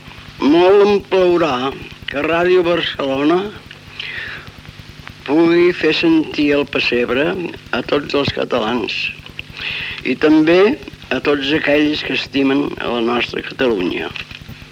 Paraules de Pau Casals quan va inaugurar el Festival de Música Clàssica de Prada a l’església de Sant Miquel de Cuixà del terme comunal de Codalet, a la comarca del Conflent a la Catalunya Nord.